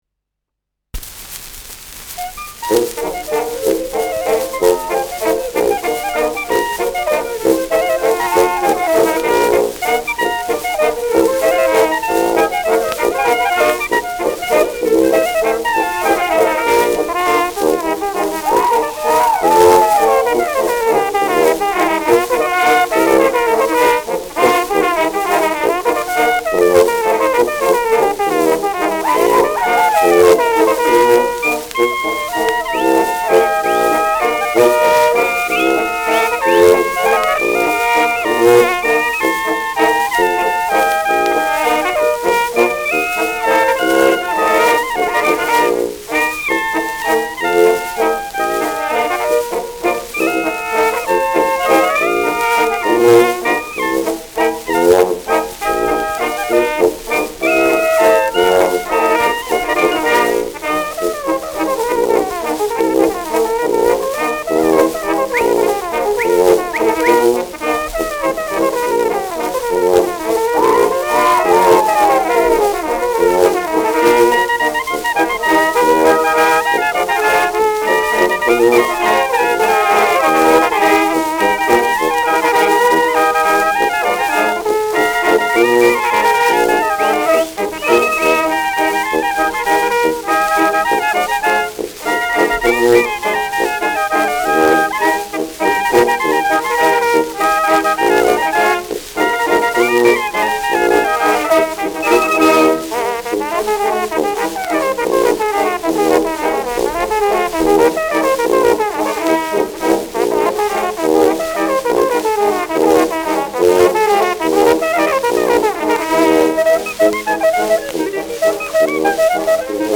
Schellackplatte
leichtes Rauschen
[Nürnberg] (Aufnahmeort)